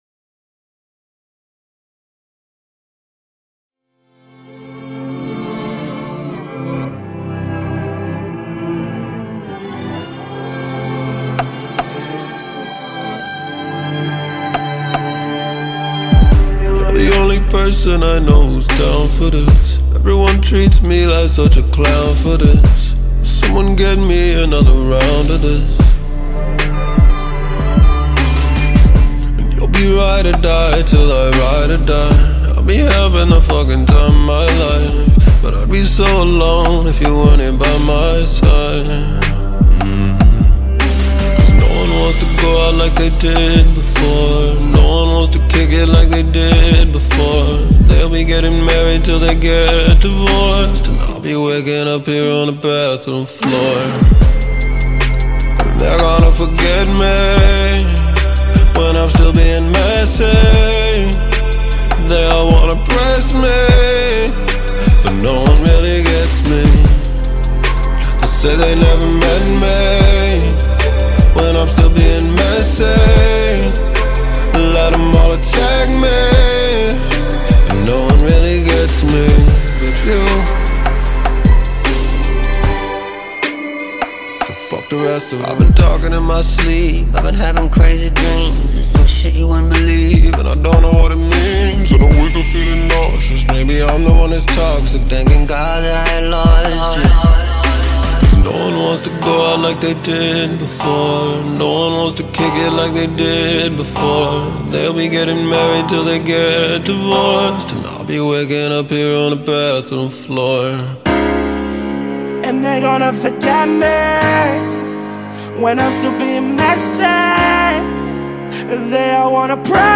slamming song